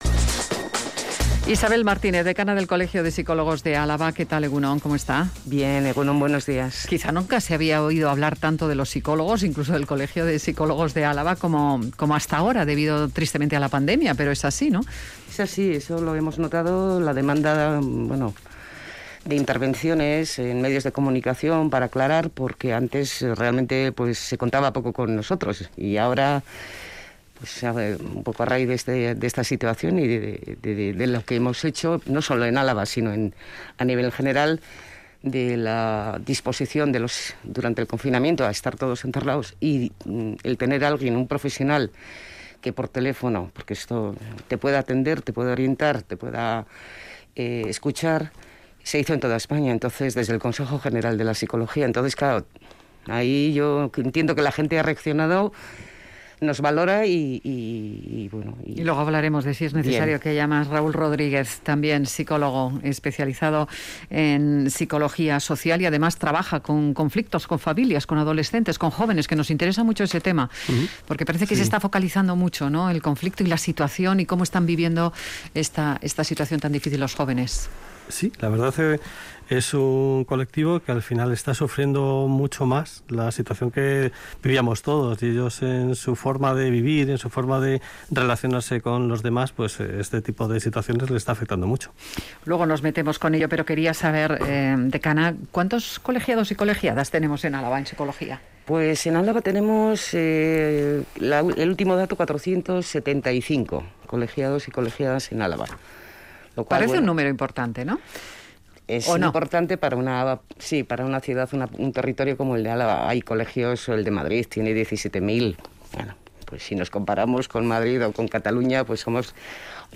Entrevistados en Radio Vitoria